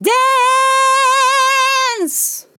Dance Sample
Dance Vocal Sample
Tags: Dance, DISCO VIBES, dry, english, female, LYRICS, sample
POLI-LYRICS-Fills-120bpm-Fm-2.wav